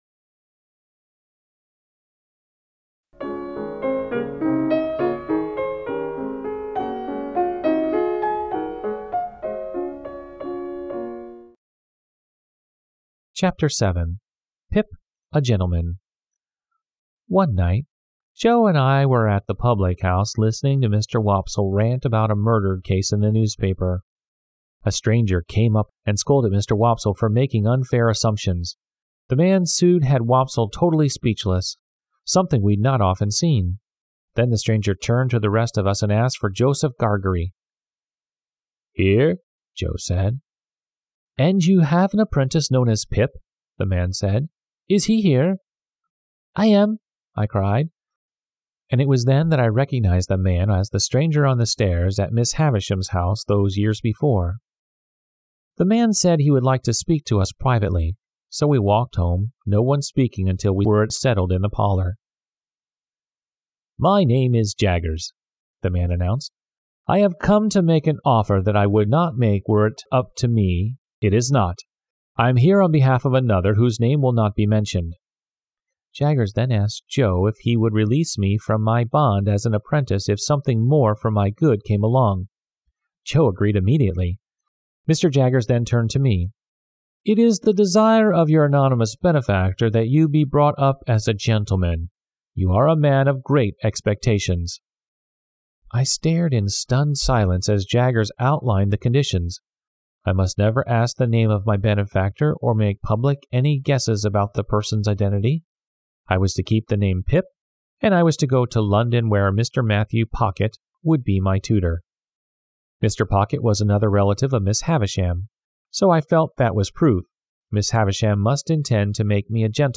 丛书甄选优质中文译本，配以导读、作家作品简介和插图，并聘请资深高考听力卷主播朗读英语有声书。